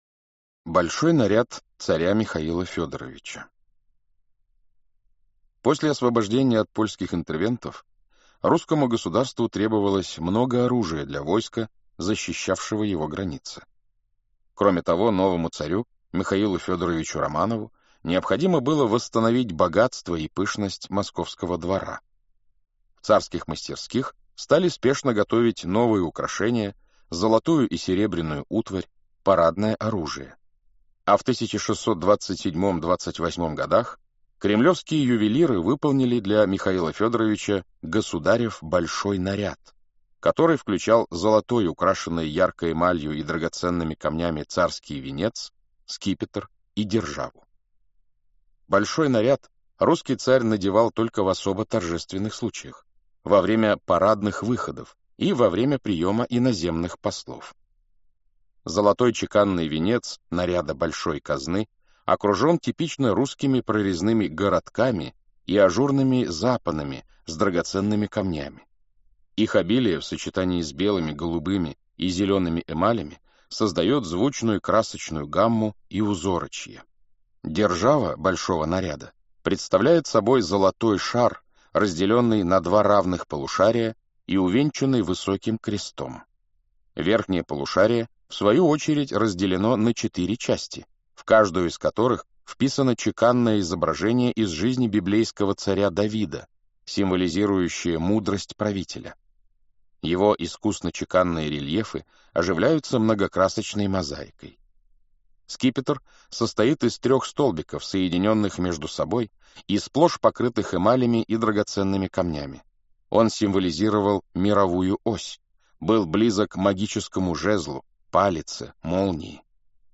Aудиокнига Великие сокровища Автор Сборник Читает аудиокнигу Александр Клюквин.